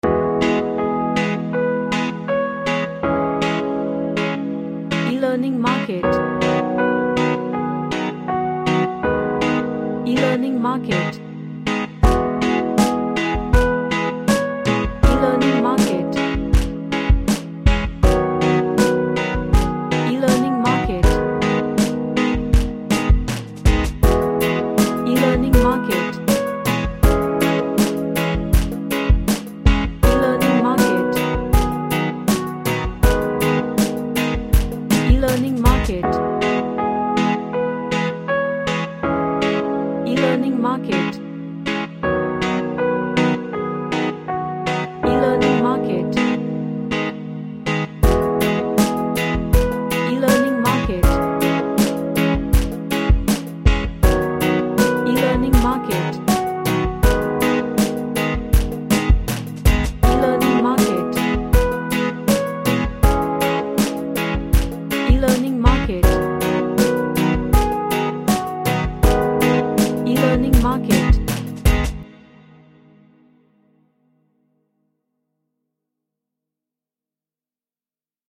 A reggae track with piano melody.
Chill Out